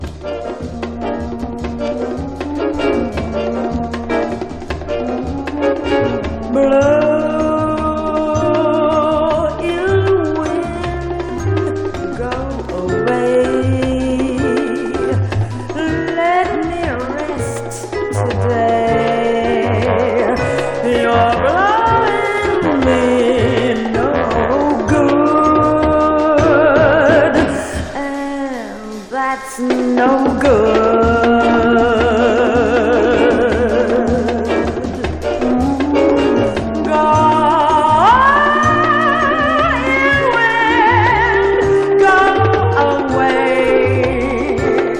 こちらは、ジャズ・ボーカル作品。
表現力が高いボーカルは、飽きのこない伸びやかさ。音もアレンジも妙技と思える最高さで彩り。
Jazz, Pop, Vocal　USA　12inchレコード　33rpm　Mono